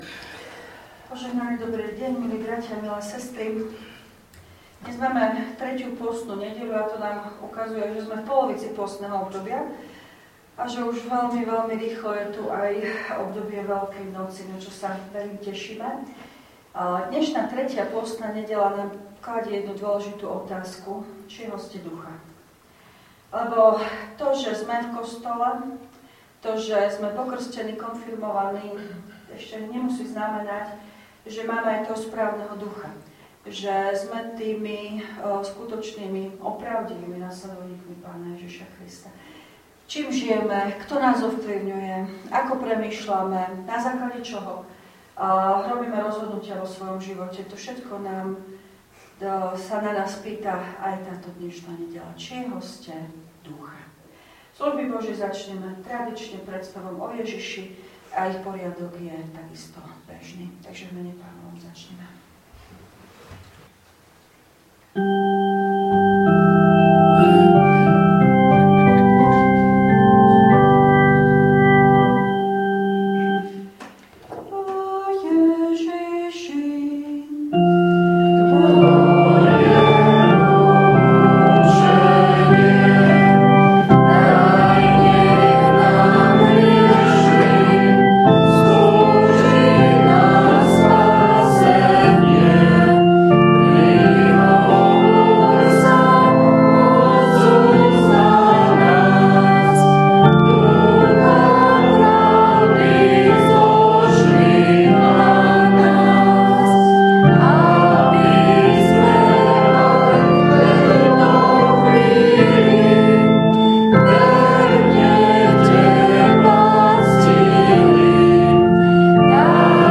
Služby Božie – 3. nedeľa pôstna
V nasledovnom článku si môžete vypočuť zvukový záznam zo služieb Božích – 3. nedeľa pôstna.